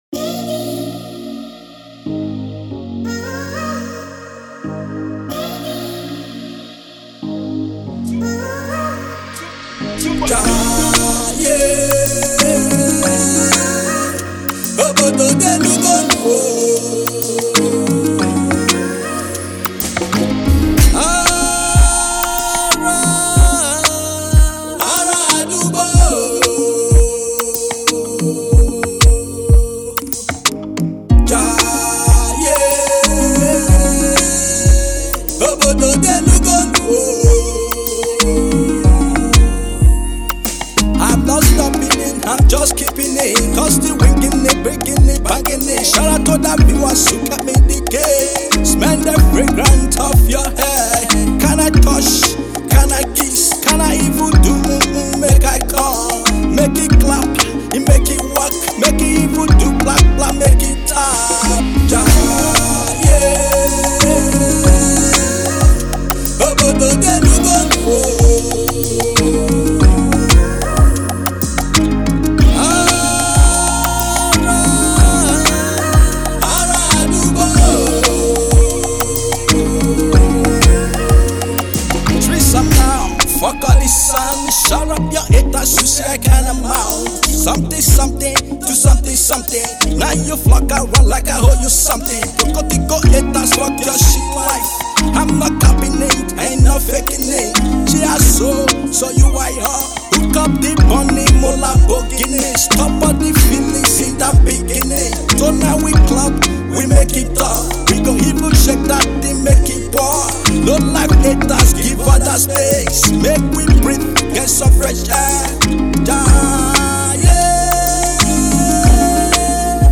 The genres are Afrobeat and Afro-fusion.